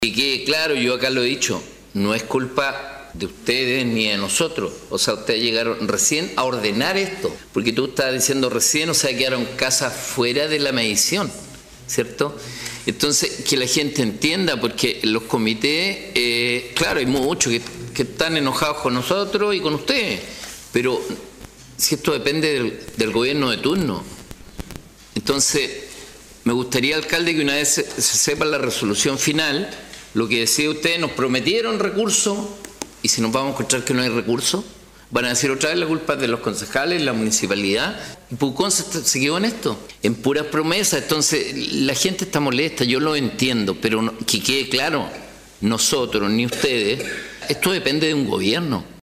En el último Concejo comunal se conocieron los enormes avances que ha tenido en torno al tema una zona que también espera el aporte, con recursos, del Ministerio de Vivienda y Urbanismo (MINVU).